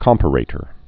(kŏmpə-rātər, kəm-părə-)